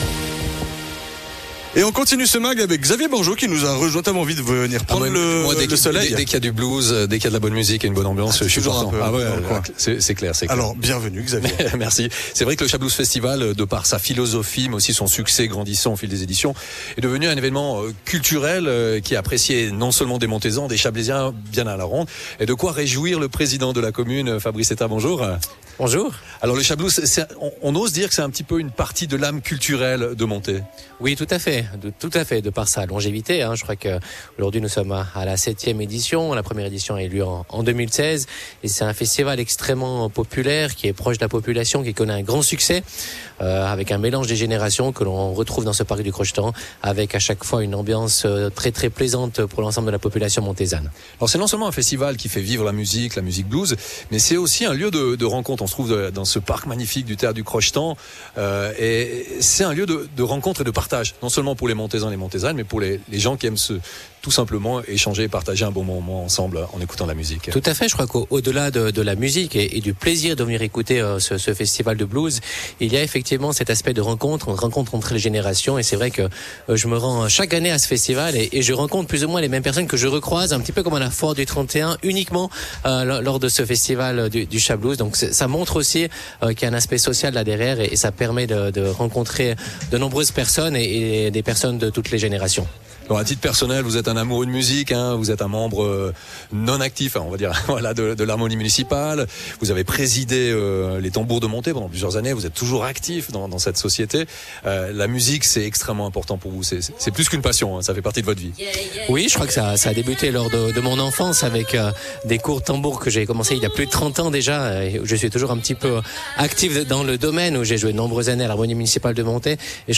Intervenant(e) : Fabrice Thétaz, Président de Monthey